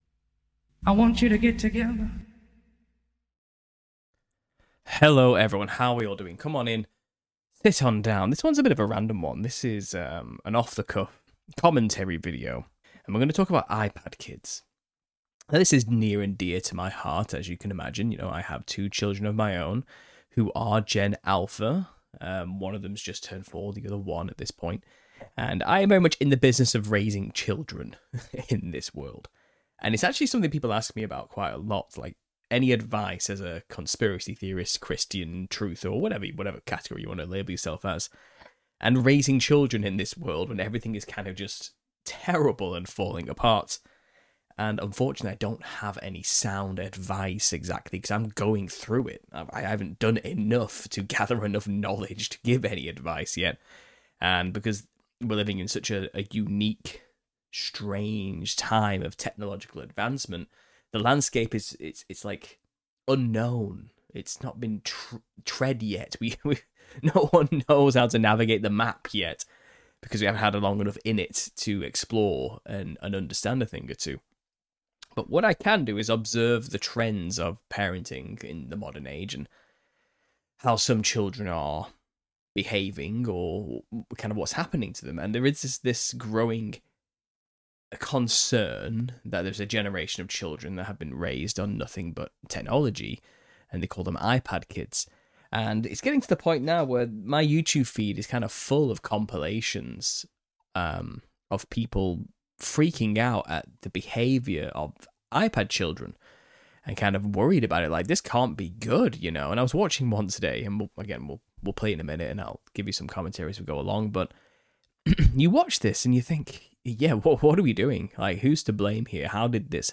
In this off-the-cuff commentary video, we delve into the growing concern surrounding 'iPad Kids' and their behavioral implications. With firsthand insights from a parent of Generation Alpha children, we explore the challenges of raising children in a technology-dominated world. This video also covers the impact of technology on literacy rates, attention spans, and overall societal well-being.